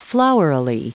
Prononciation du mot : flowerily